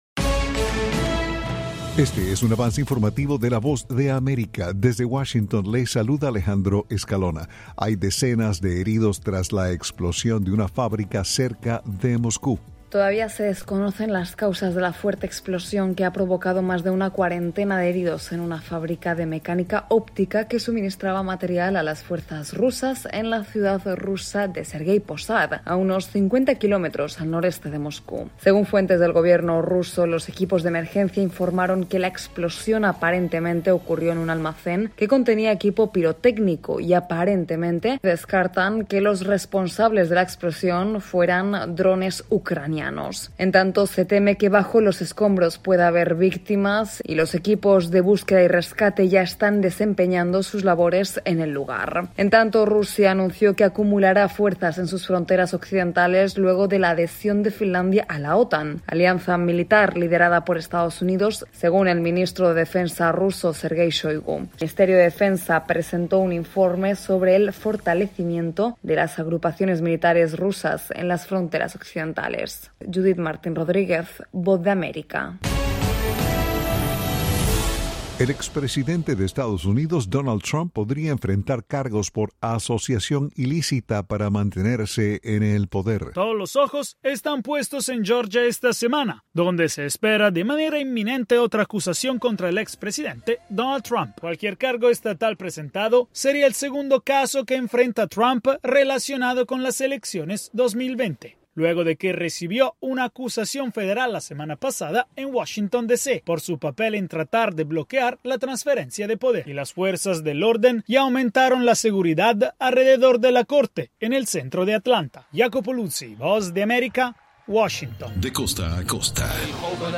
Este es un avance informativo presentado por la Voz de América en Washington.